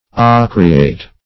Search Result for " ocreate" : The Collaborative International Dictionary of English v.0.48: Ocreate \O"cre*ate\, Ocreated \O"cre*a"ted\, a. [See Ochrea .]